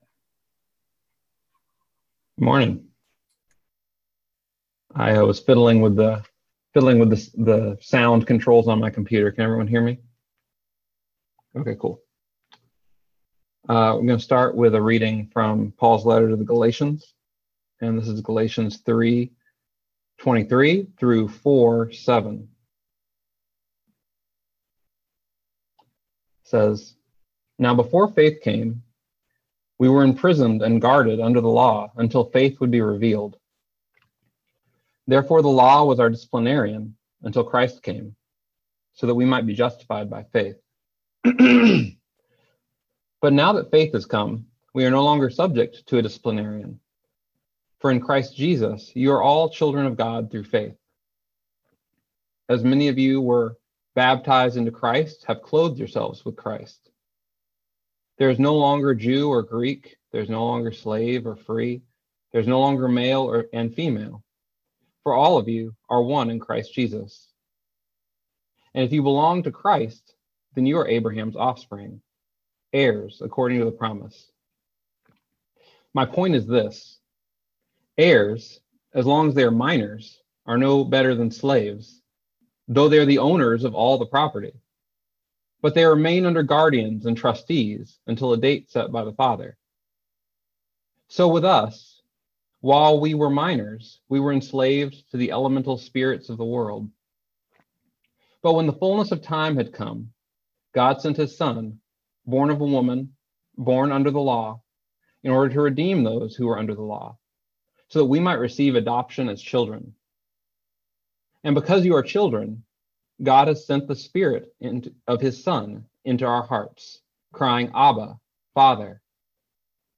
Listen to the most recent message from Sunday worship at Berkeley Friends Church, “Now We Are Children.”